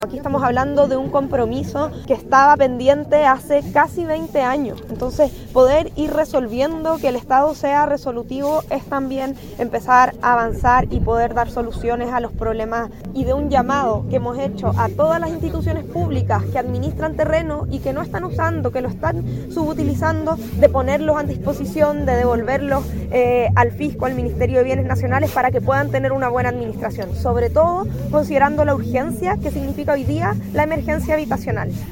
Autoridades participaron este viernes de la ceremonia de traspaso por parte del Ejército a Bienes Nacionales del fundo Quitaluto en Corral, región de Los Ríos.
La ministra de Bienes Nacionales, Javiera Toro, aprovechó la instancia para llamar a las instituciones públicas que tienen terrenos sin uso a dejarlos a disposición del fisco para poder resolver en parte la crisis habitacional.